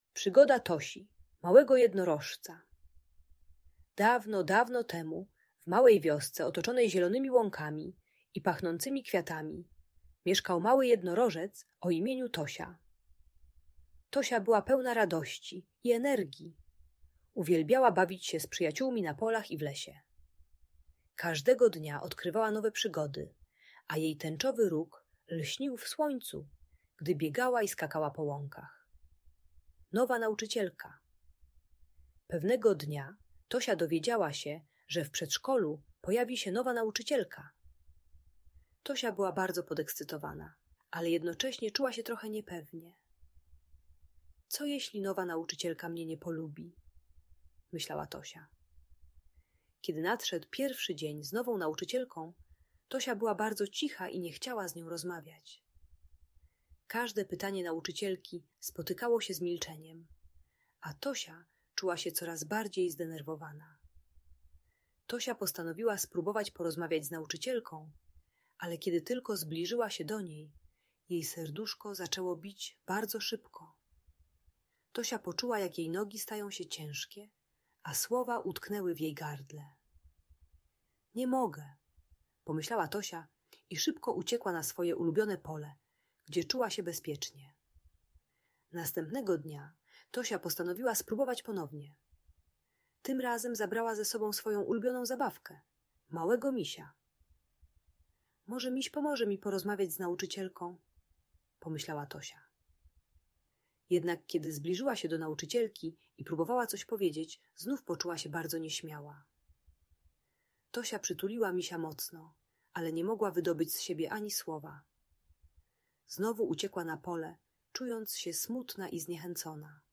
Przygoda Tosi, małego jednorożca - Audiobajka